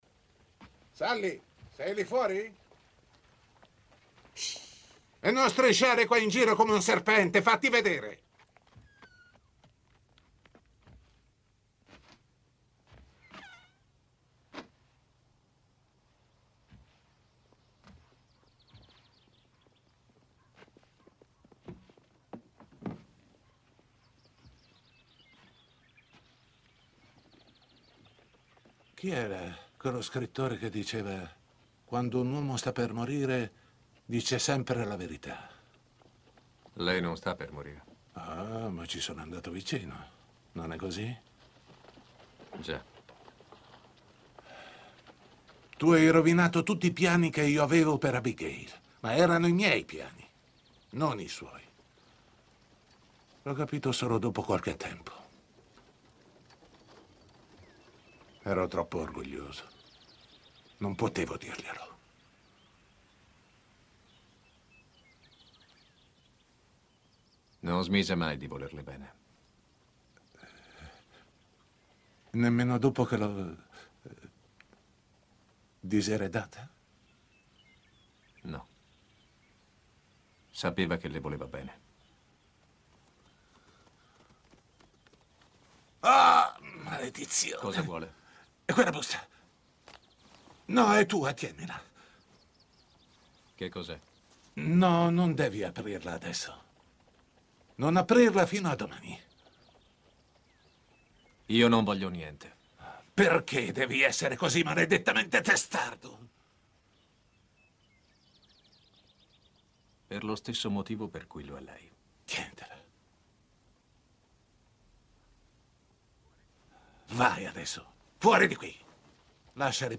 voce di Carlo Bonomi nel telefilm "La signora del West", in cui doppia Orson Bean.